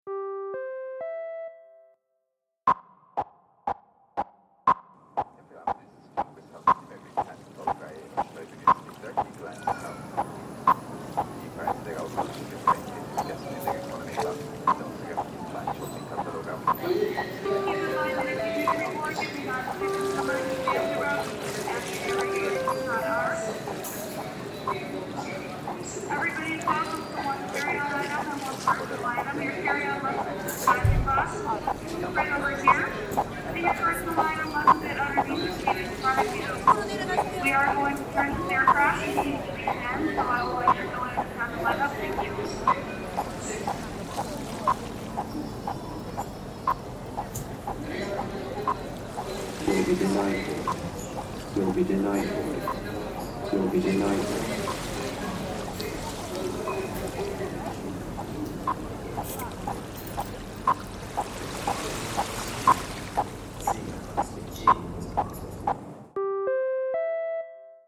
Jeddah airport, reimagined